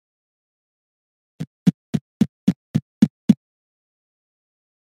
Звук быстрых шагов в Roblox